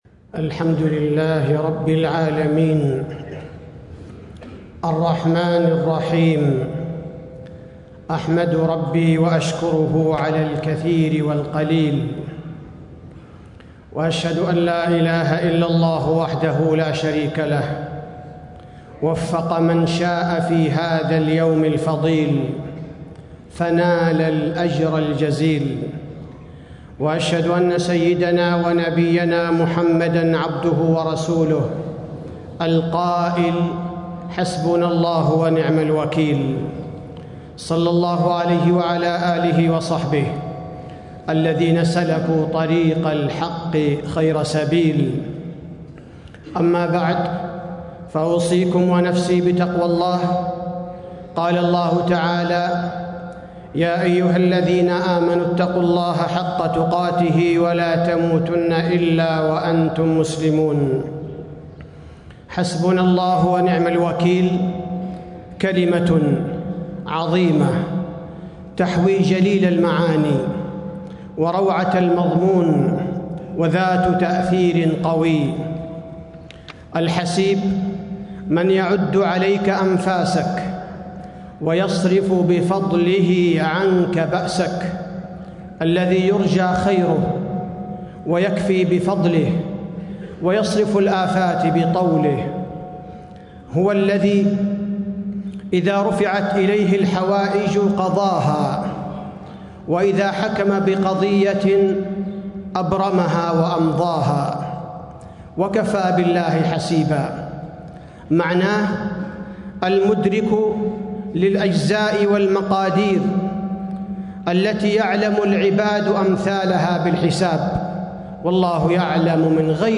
تاريخ النشر ٤ ربيع الأول ١٤٣٦ هـ المكان: المسجد النبوي الشيخ: فضيلة الشيخ عبدالباري الثبيتي فضيلة الشيخ عبدالباري الثبيتي حسبنا الله ونعم الوكيل معناها وفضلها